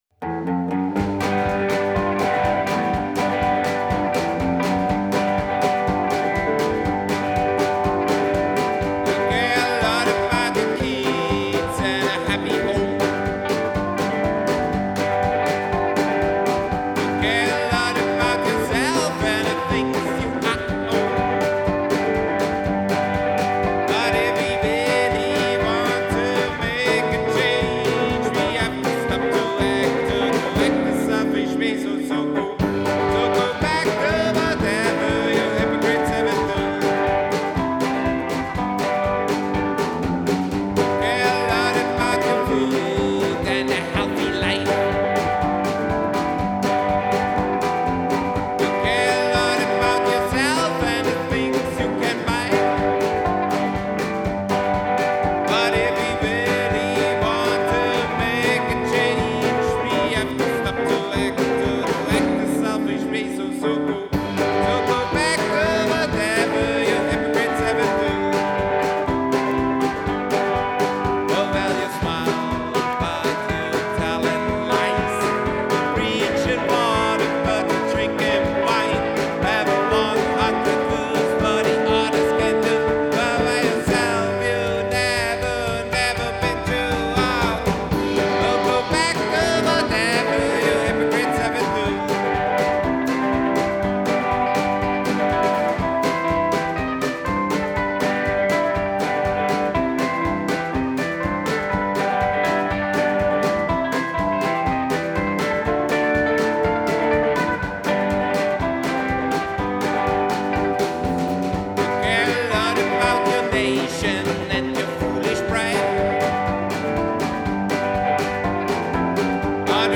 Genre: Psychobilly, Rockabilly, Country